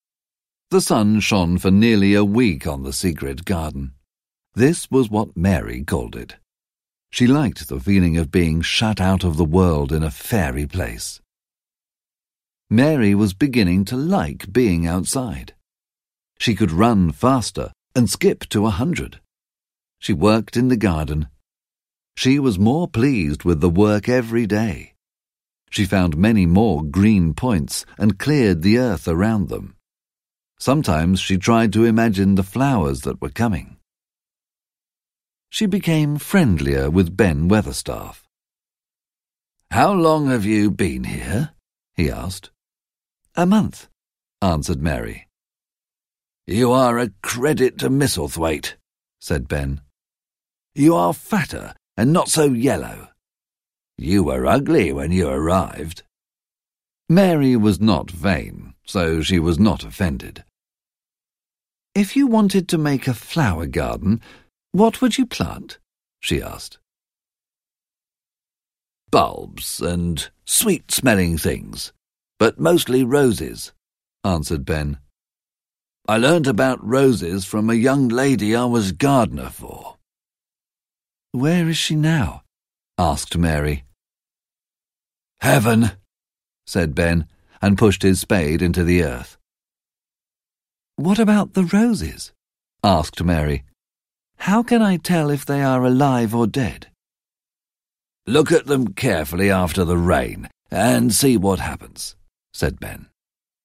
The Secret Garden (EN) audiokniha
Ukázka z knihy